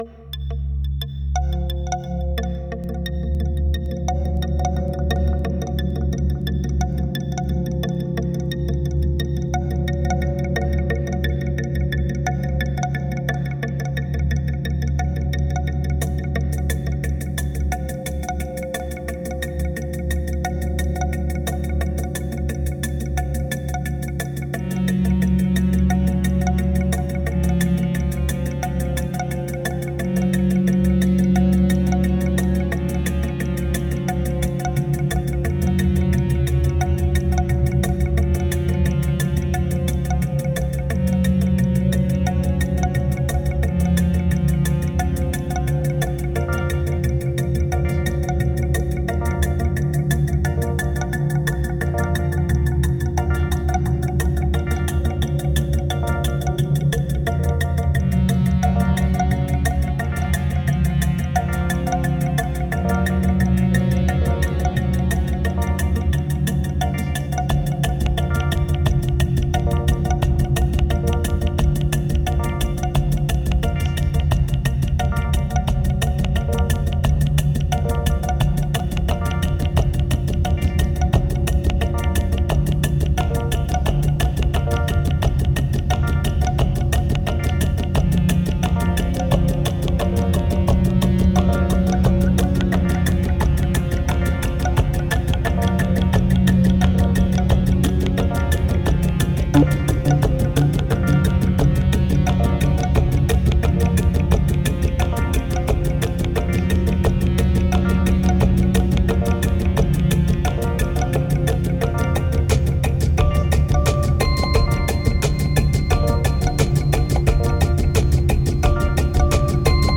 No sound design here. Ambient maybe ?
2135📈 - 55%🤔 - 88BPM🔊 - 2013-08-09📅 - 41🌟